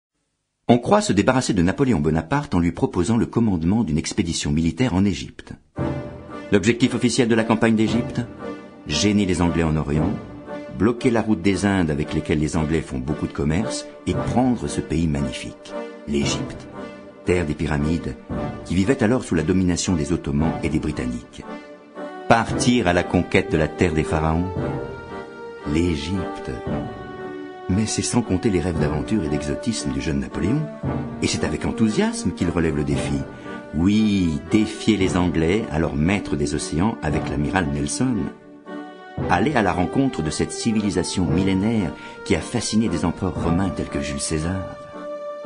Diffusion distribution ebook et livre audio - Catalogue livres numériques
Nous allons parcourir le monde, de la naissance de Napoléon en Corse, à l'Italie, de l'Egypte à la Russie, cette odyssée s'achevant sur l'île de Sainte Hélène, au milieu de l'océan Atlantique. Le texte et l'interprétation sont mis en valeur par une musique originale.